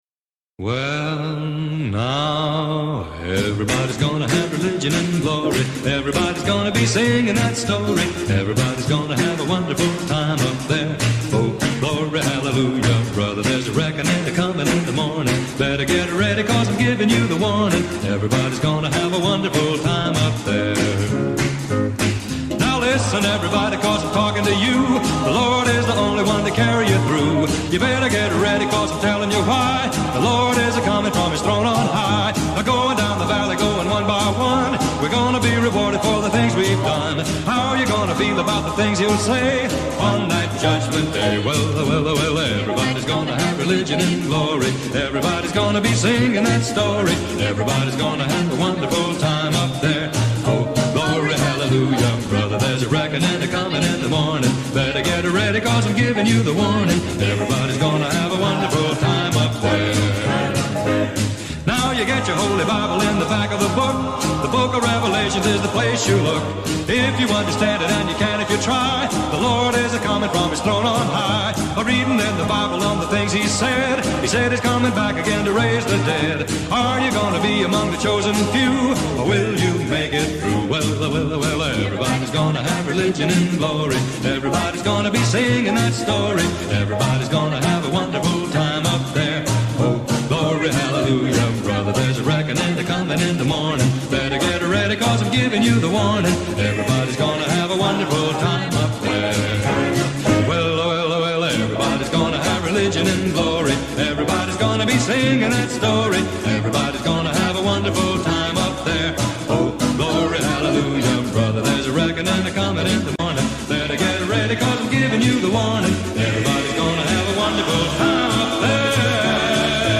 Church Boogie .
. consisting of church songs put to a boogie beat, such as .